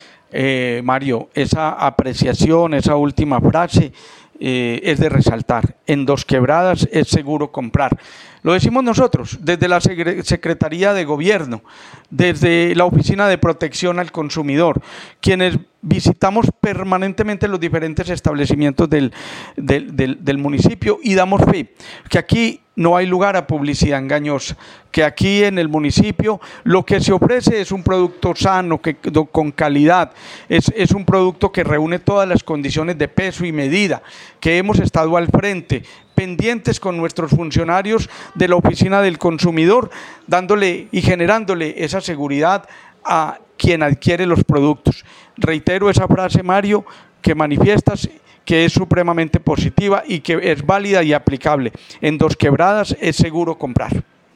Comunicado-630-Audio-Sec.-Gobierno-Juan-Carlos-Sepulveda.mp3